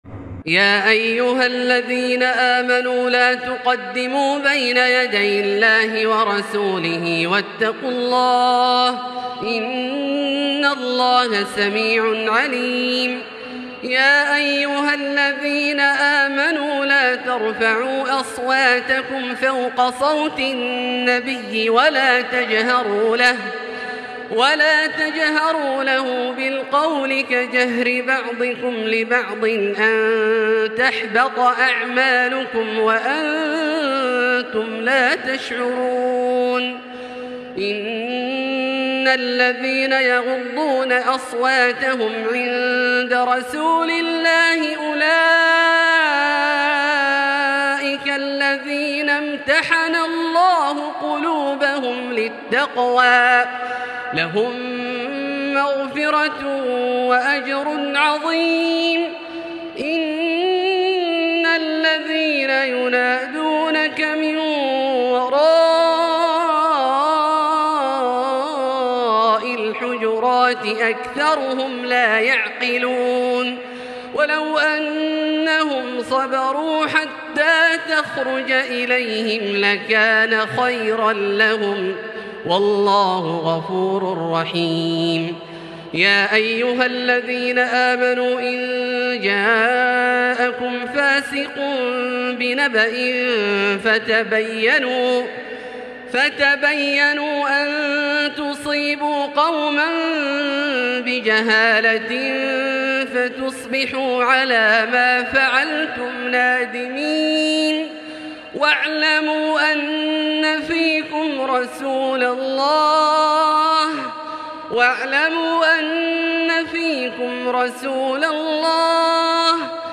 تلاوة ملئت روحانية لـ سورة الحجرات كاملة للشيخ د. عبدالله الجهني من المسجد الحرام | Surat Al-Hujurat > تصوير مرئي للسور الكاملة من المسجد الحرام 🕋 > المزيد - تلاوات عبدالله الجهني